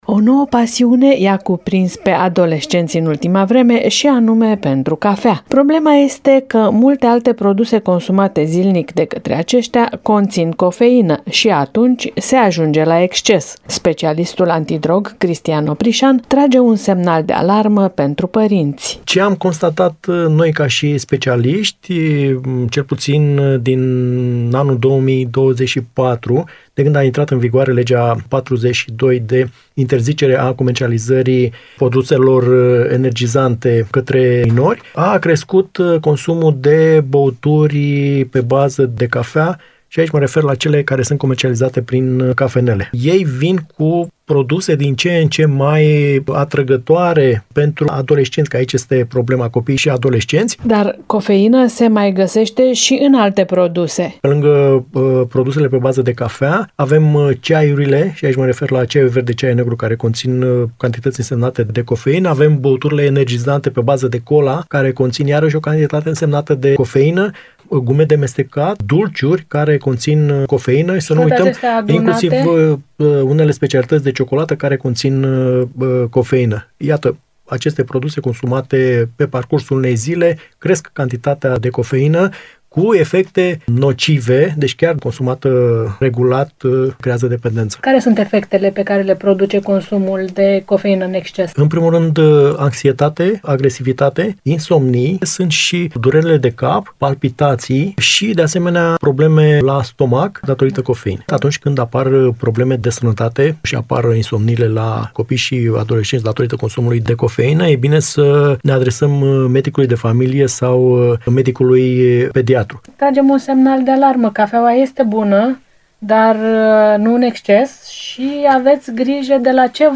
Interviu cu specialistul antidrog